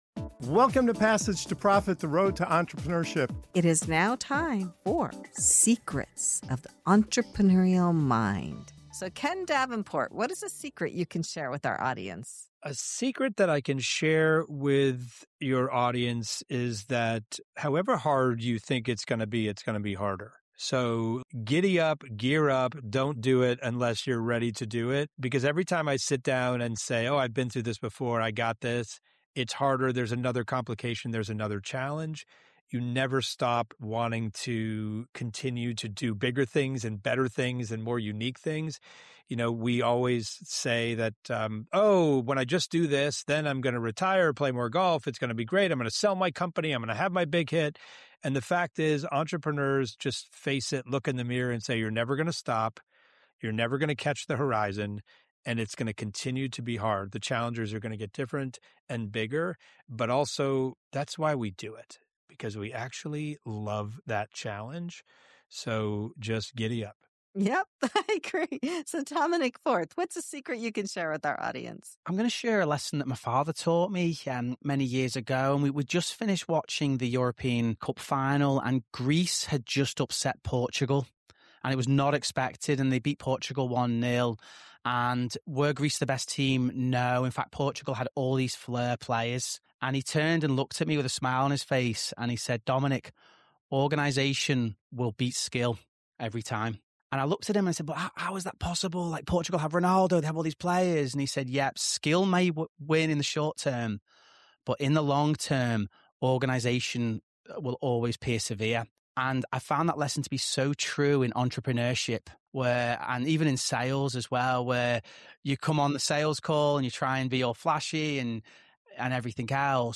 In this powerful “Secrets of the Entrepreneurial Mind” segment on Passage to Profit Show, top founders and business leaders reveal the unfiltered truth behind growth, resilience, and long-term success.